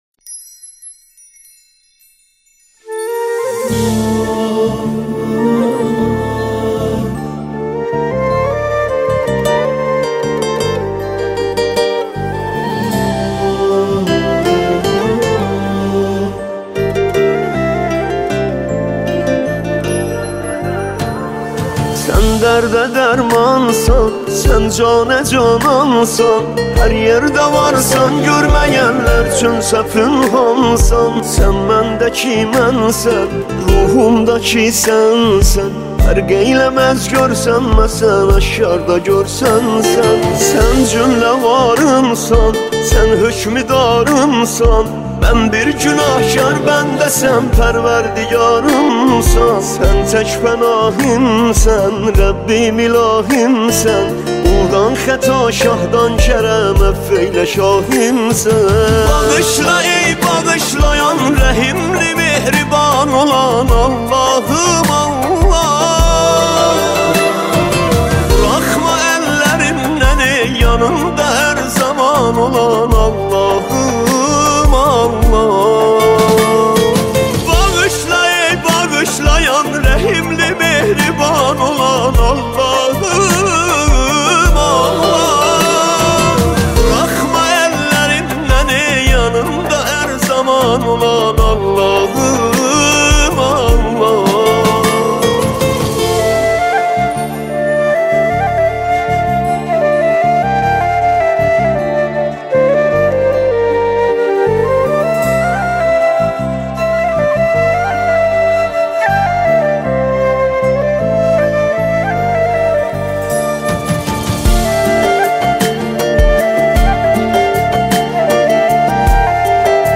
مناجات ترکی زیبا و دلنشین